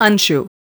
Customar Dj Studio Voices Tag